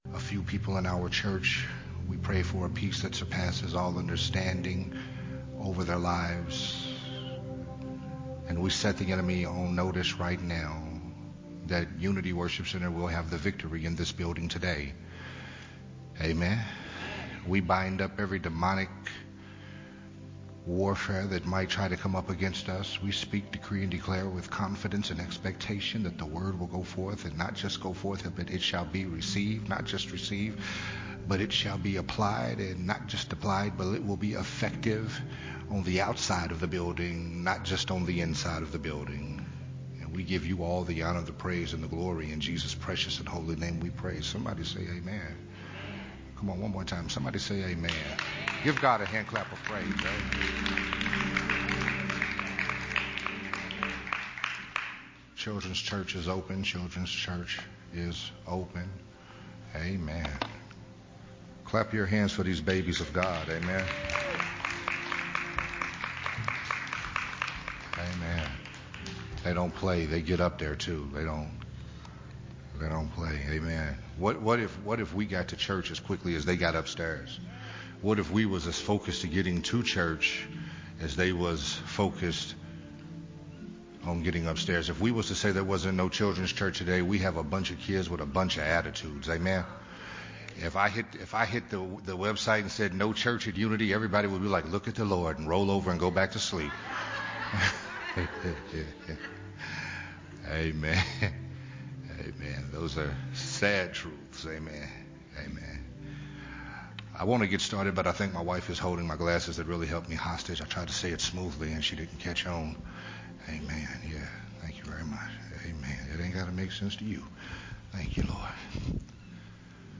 recorded at Unity Worship Center on June 6th, 2021.